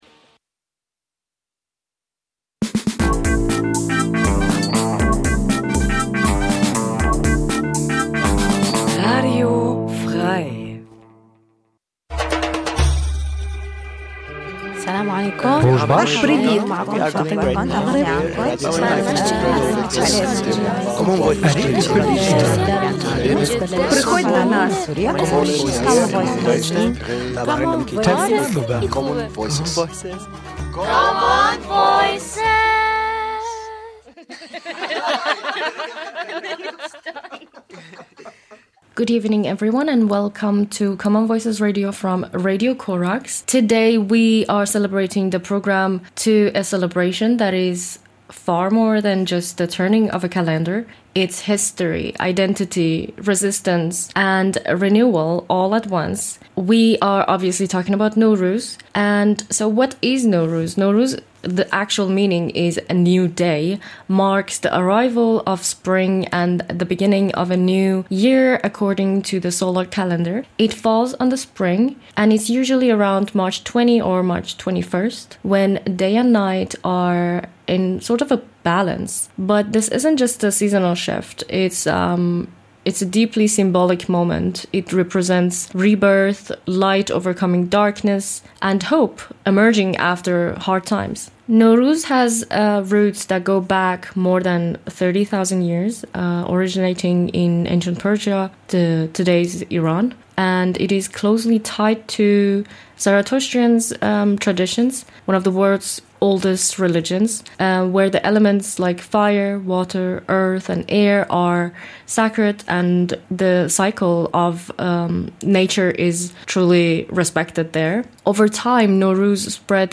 Seit 2016 gibt es bei unserem Schwesternradio Radio Corax die mehrsprachige Sendung Common Voices. Es ist eine Sendung von Gefl�chteten und MigrantInnen in Halle und Umgebung.